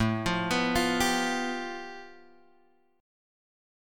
A7sus2#5 chord {5 x 3 4 6 3} chord